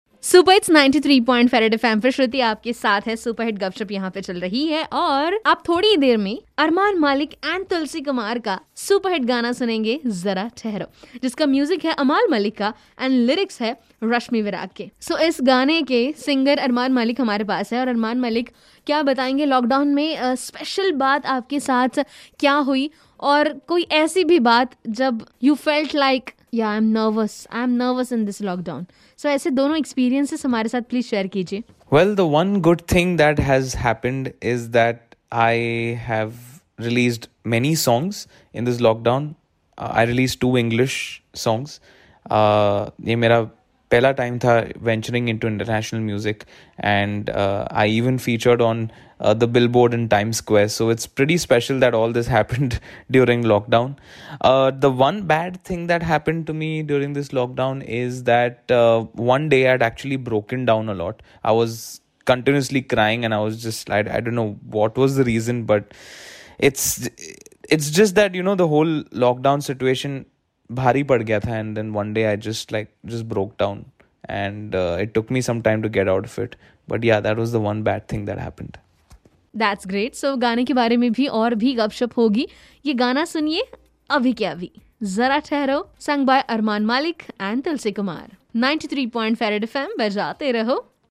GUPSHUP WITH SINGER ARMAAN MALIK